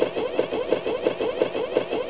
1 channel
parts_sound_1plegswalk.wav